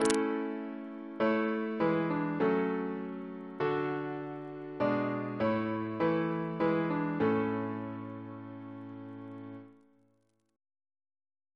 CCP: Chant sampler
Single chant in A Composer: William Hayes (1707-1777) Reference psalters: ACB: 88; OCB: 276; PP/SNCB: 76